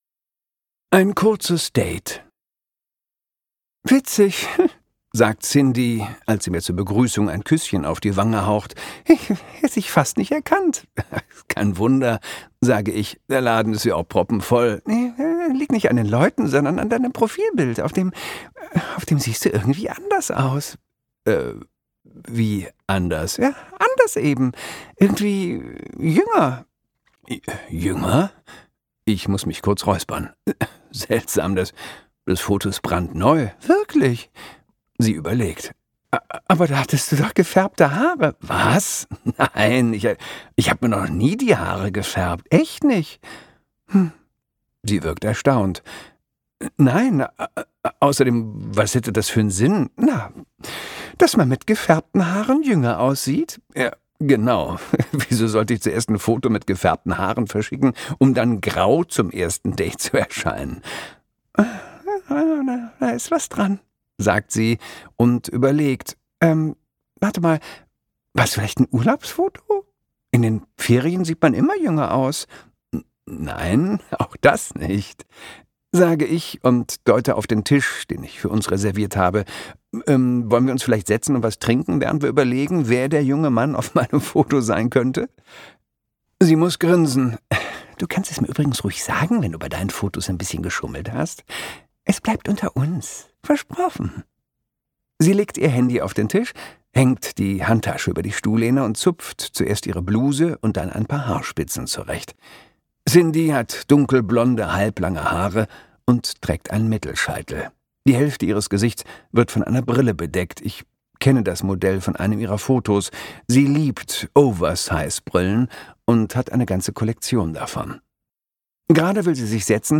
Gekürzt Autorisierte, d.h. von Autor:innen und / oder Verlagen freigegebene, bearbeitete Fassung.
Wir Freitagsmänner Gelesen von: Christoph Maria Herbst